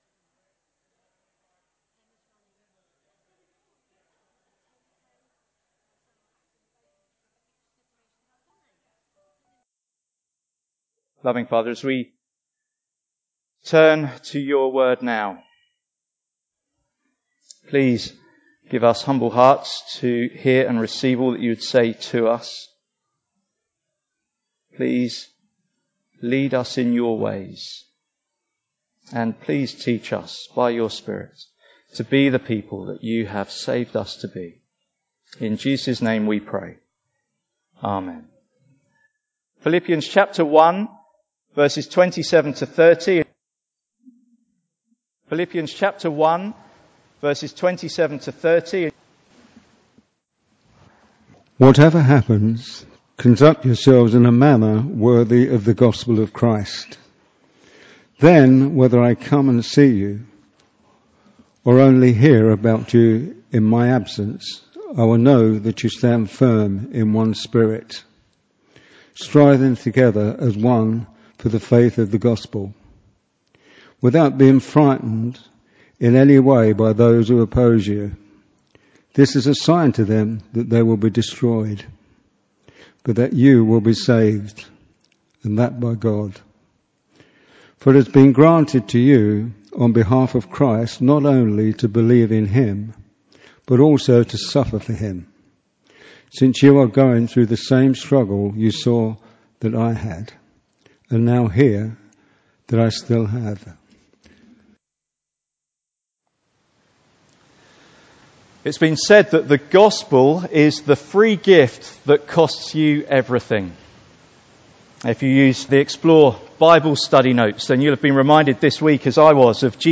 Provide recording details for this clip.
Passage: Philippians 1:27-30 Service Type: Sunday Morning